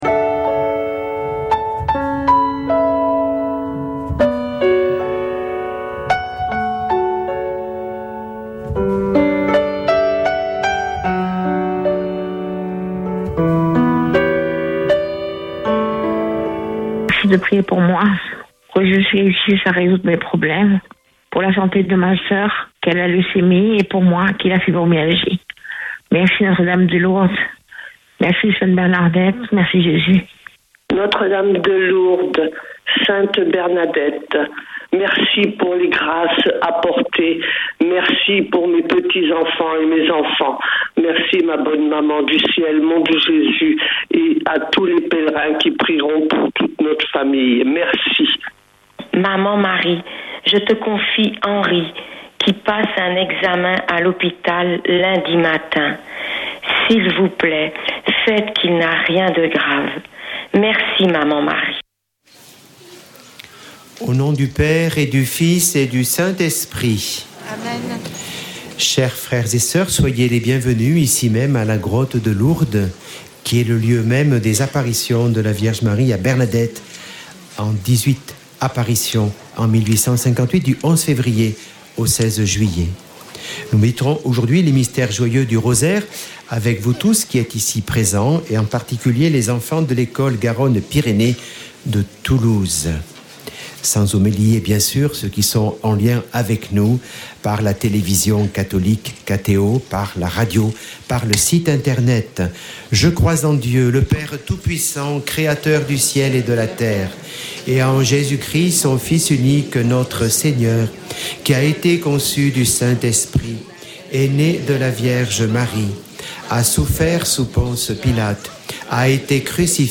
Chapelet de Lourdes du 27 sept.
Une émission présentée par Chapelains de Lourdes